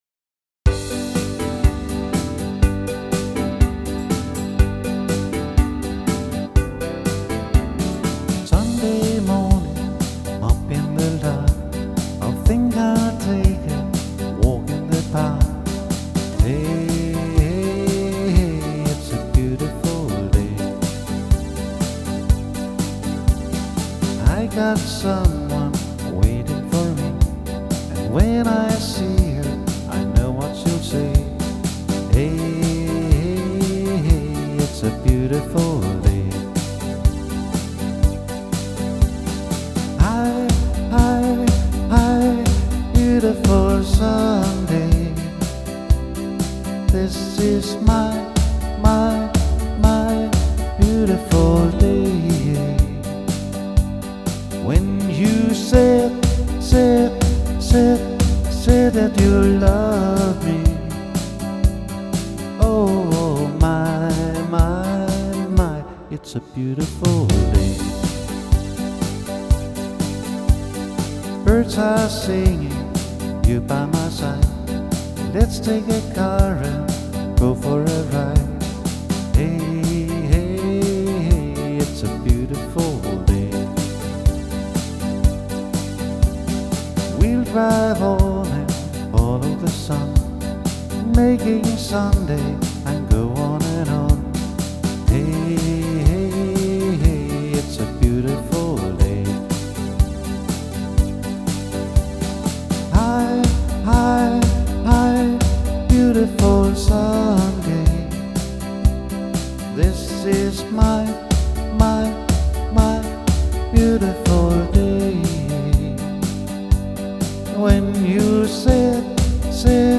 • Solomusiker